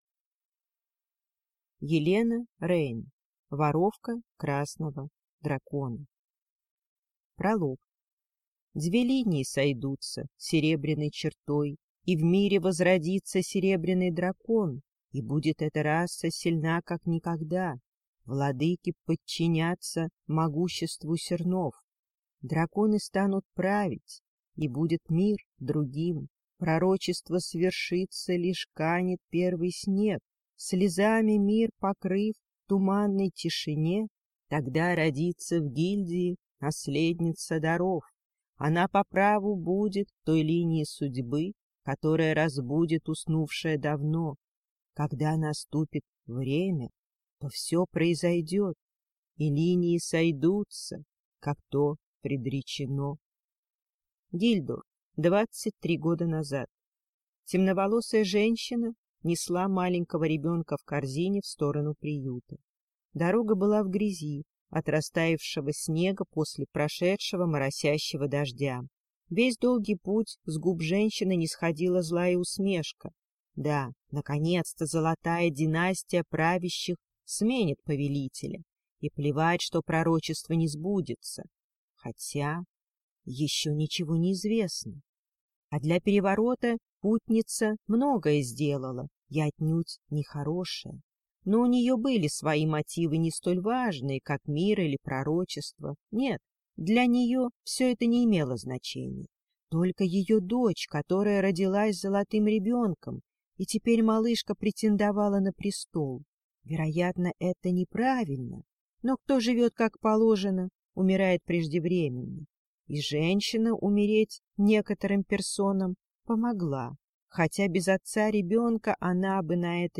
Аудиокнига Воровка красного дракона | Библиотека аудиокниг